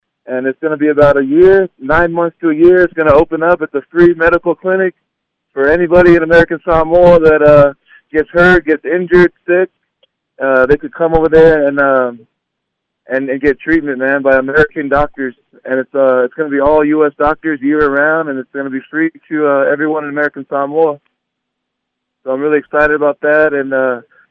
Denver Broncos nose tackle Domato Peko got emotional when he spoke yesterday about his reason for wanting to support the new health center being built by Hope Mission Ministries in Tafuna.